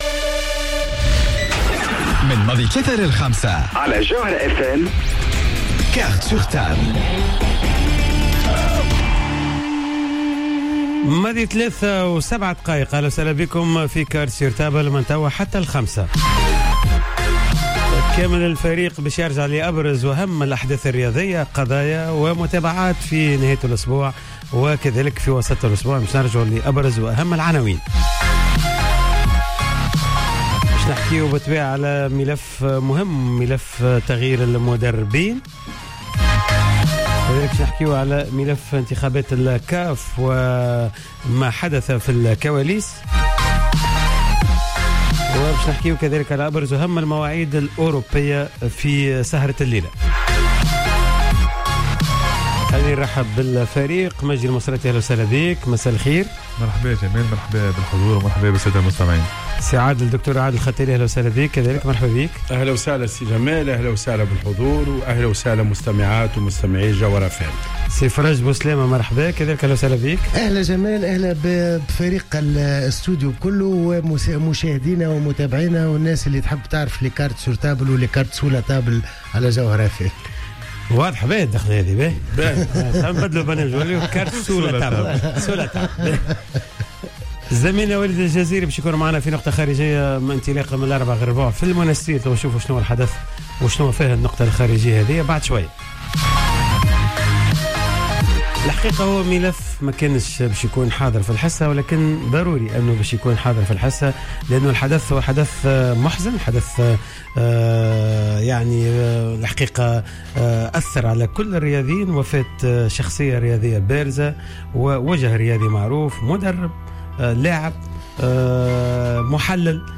وتم خلال الجزء الاول من الحصة تشريك العديد من نجوم الرياضة في تونس و الجماهير الرياضية الذين تحدثوا بتأثر شديد عن رحيل الفقيد .